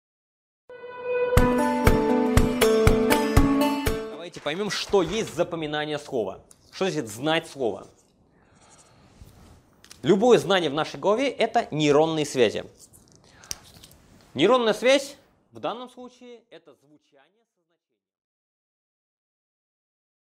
Аудиокнига Как запоминать иностранные слова. Метод фонетических ассоциаций | Библиотека аудиокниг